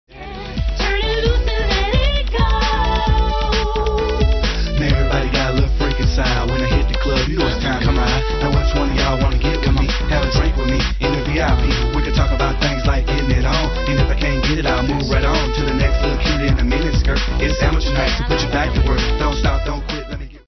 disco
stereo